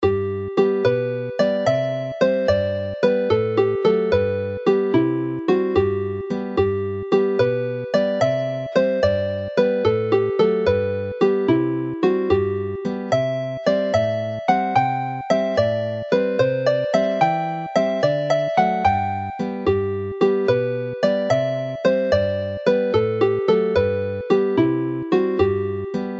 Play the tune slowly